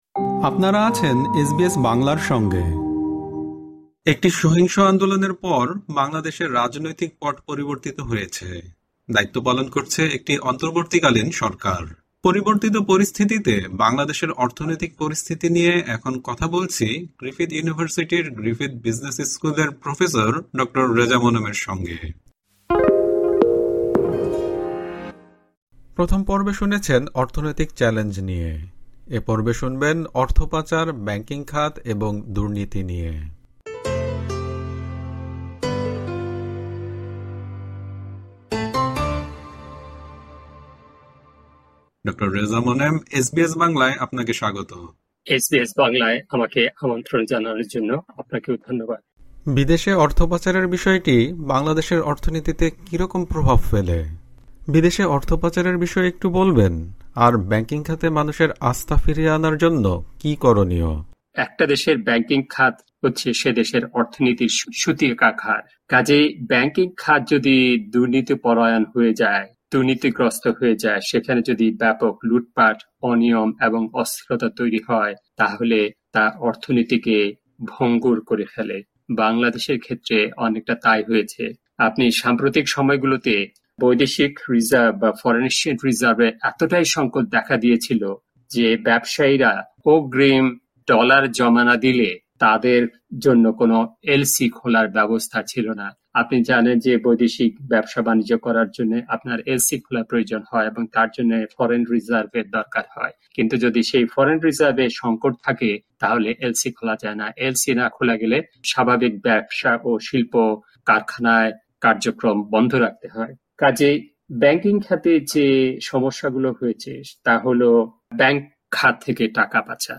তার সাক্ষাৎকারের এই (দ্বিতীয়) পর্বে রয়েছে অর্থ পাচার, ব্যাংকিং খাত এবং দূর্নীতি নিয়ে আলোচনা।